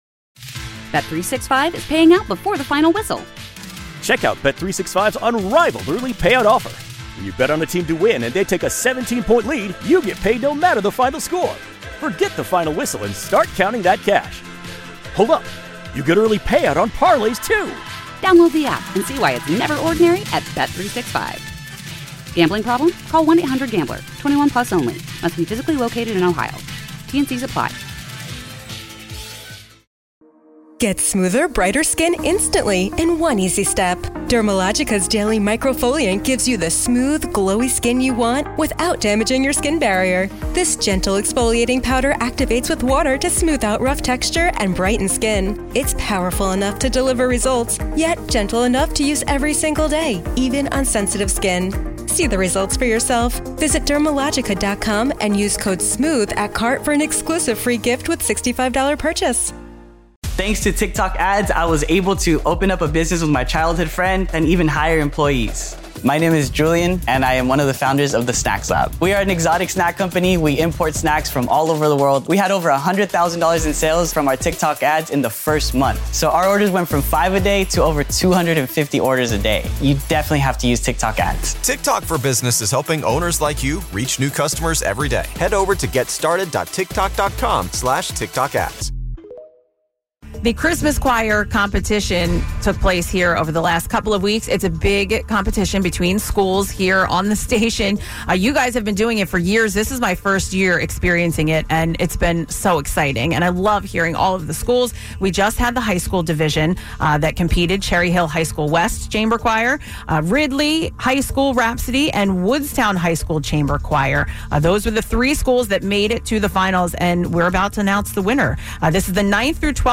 Congratulations Woodstown High School B101's 2025 Christmas Choir Competition High School Division First Place Champions 4:13